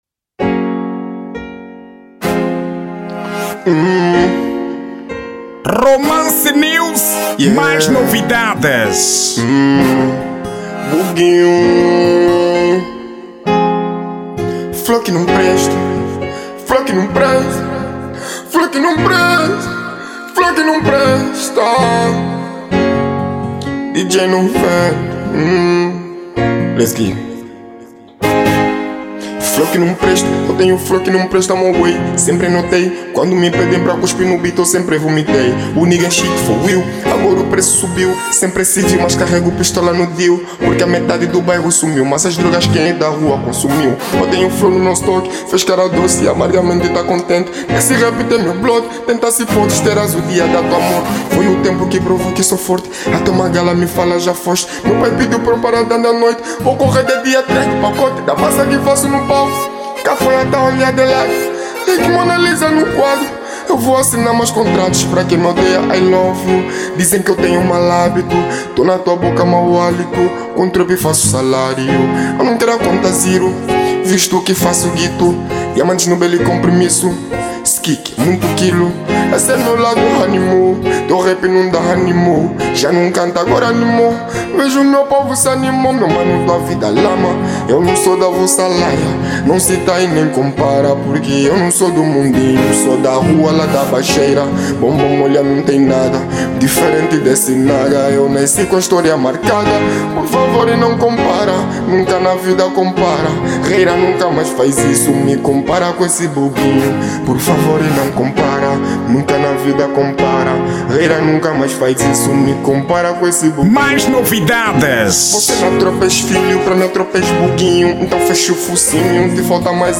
Estilo: Rap